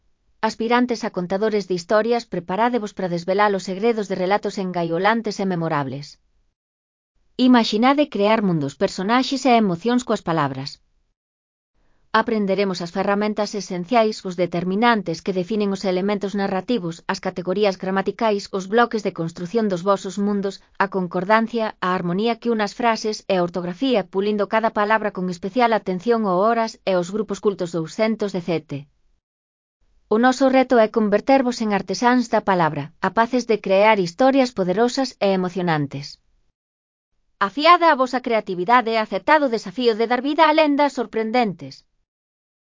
Lectura facilitada
Elaboración propia (proxecto cREAgal) con apoio de IA voz sintética xerada co modelo Celtia. Orientacións (CC BY-NC-SA)